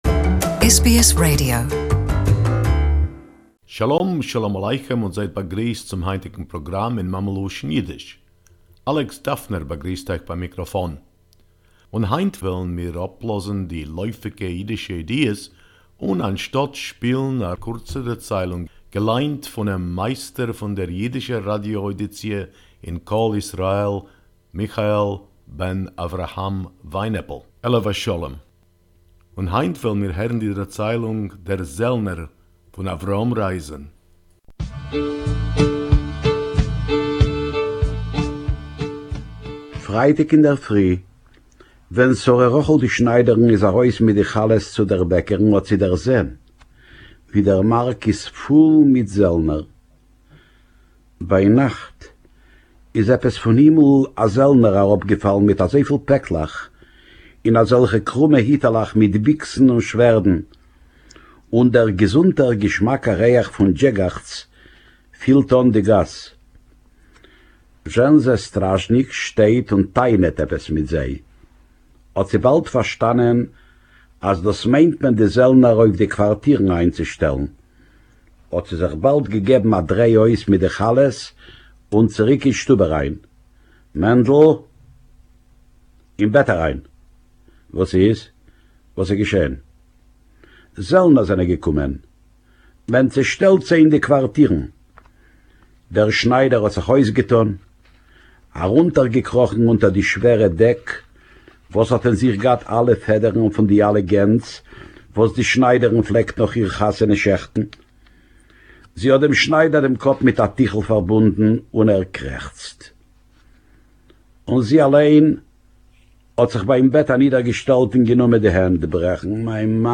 Yiddish story: “The Soldier” by Avrom Reisen